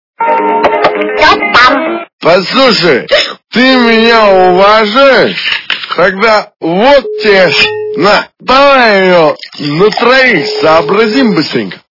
При прослушивании Кто-там? - Послушай, ты меня уважаешь, тогда давай сообразим на троих... качество понижено и присутствуют гудки.